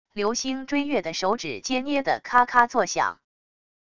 流星追月的手指皆捏得咔咔作响wav音频生成系统WAV Audio Player